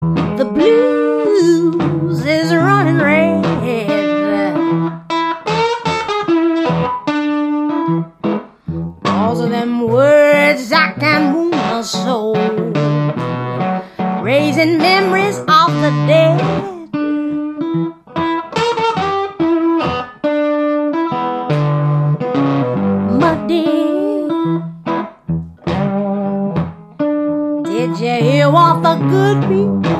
voce
Una voce di velluto tagliente.